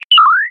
open-safe.ogg.mp3